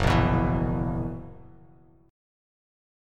F#11 chord